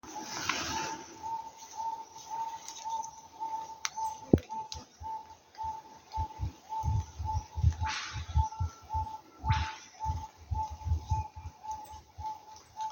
Nombre en inglés: Wonga Pigeon
Localidad o área protegida: Lamington National Park
Condición: Silvestre
Certeza: Vocalización Grabada
wonga-pigeon.mp3